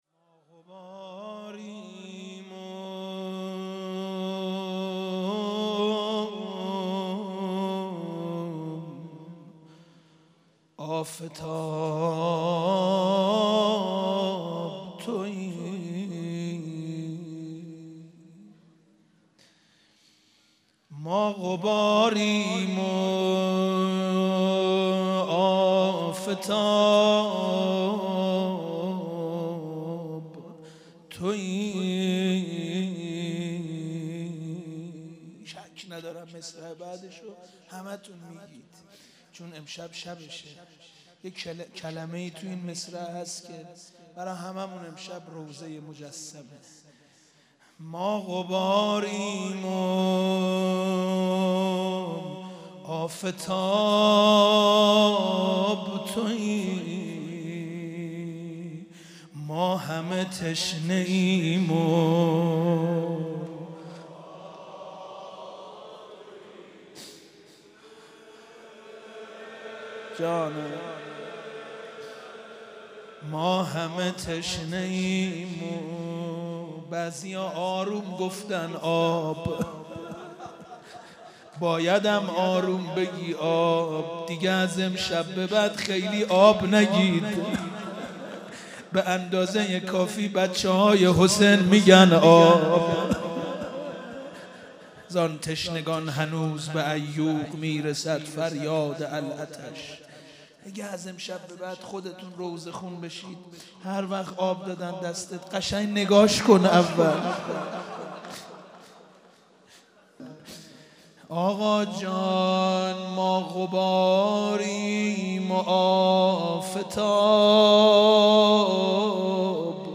شب هفتم محرم95/هیئت رزمندگان اسلام قم
مناجات